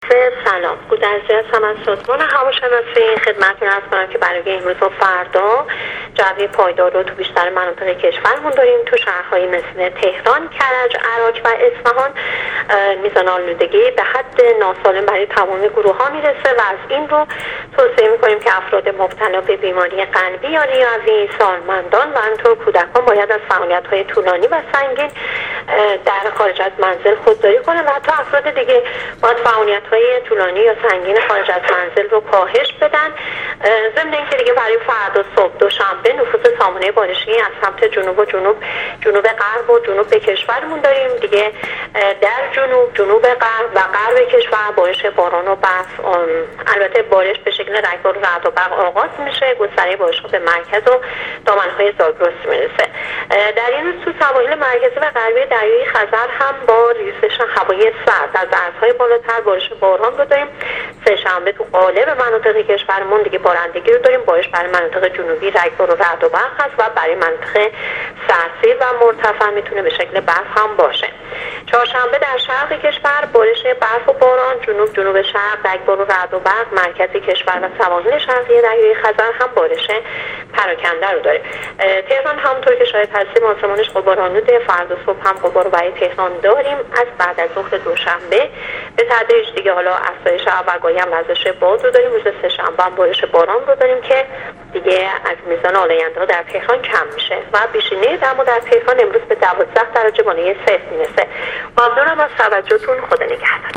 گزارش رادیو اینترنتی از آخرین وضعیت آب و هوای روز یکشنبه ۲۴ آذرماه: